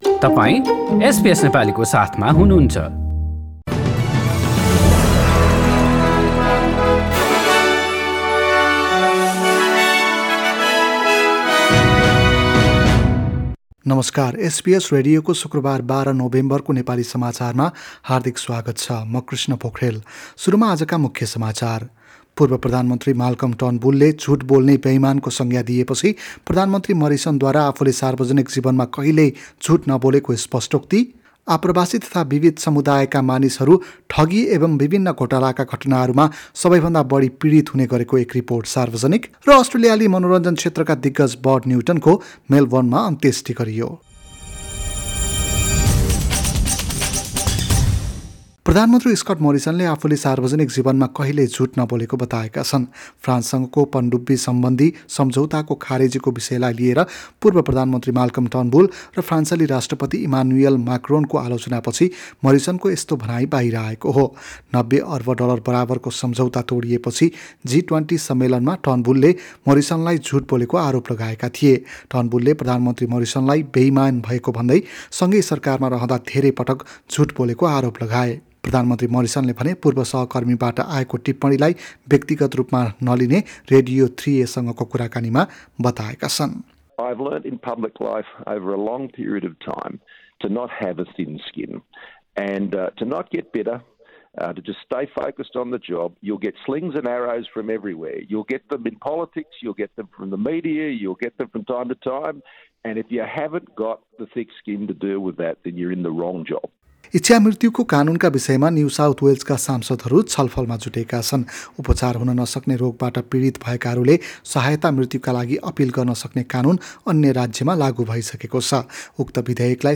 एसबीएस नेपाली अस्ट्रेलिया समाचार: शुक्रबार १२ नोभेम्बर २०२१